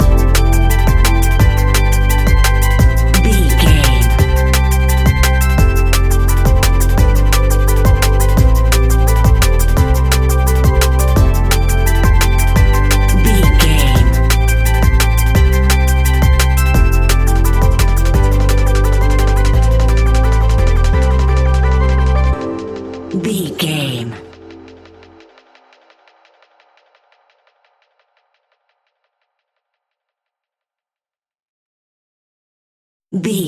Ionian/Major
techno
trance
synthesizer
synthwave